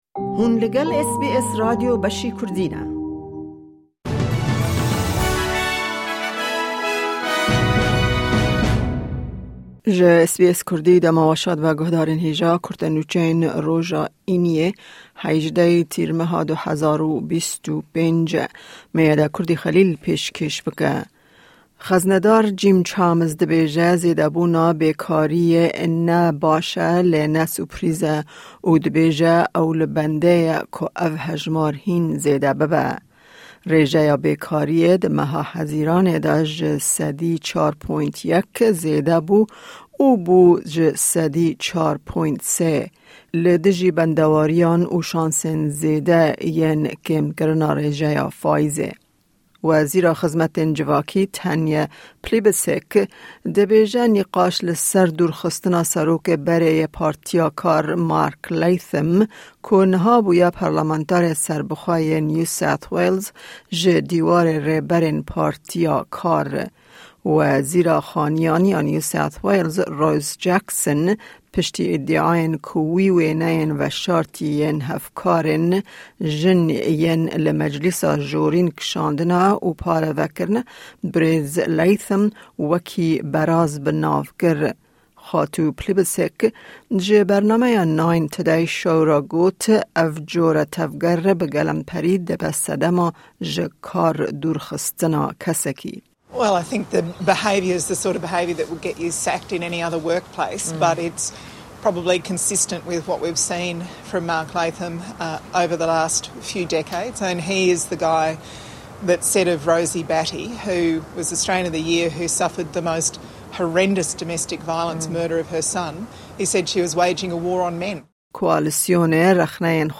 Kurte Nûçeyên roja Înî 18î Tîrmeha 2025